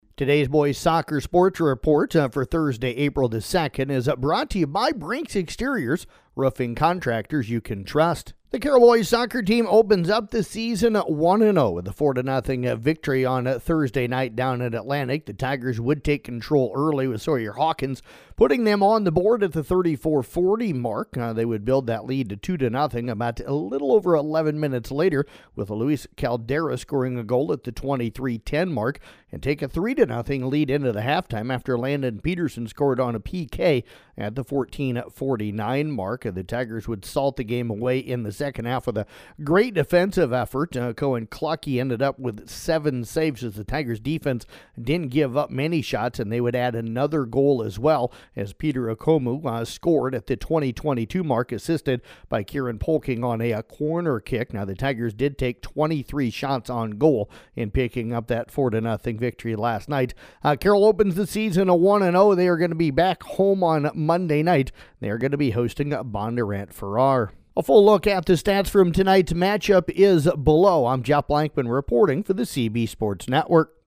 Below is an audio recap of the Boys Soccer match for Thursday, April 2nd